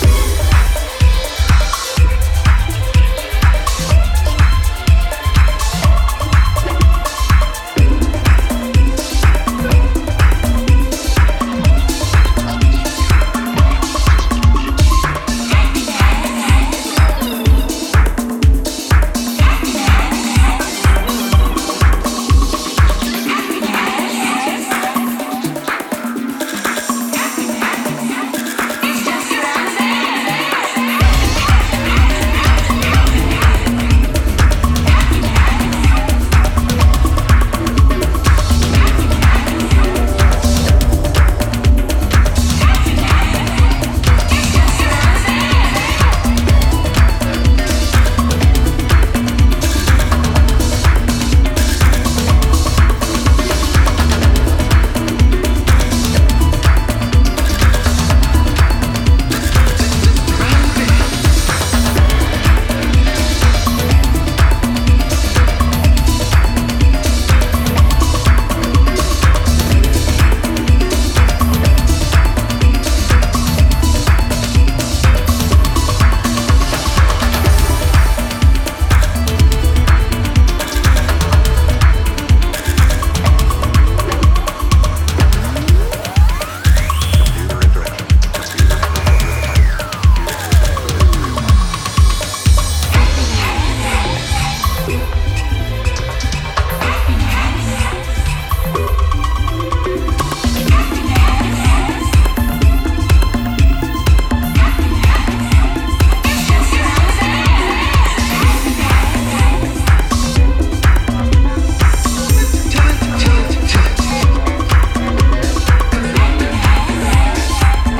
過去10年間にベルリンのクラブフロアで培われた経験が存分に発揮されている、非常に退廃的な内容ですね。